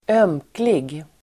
Ladda ner uttalet
Uttal: [²'öm:klig]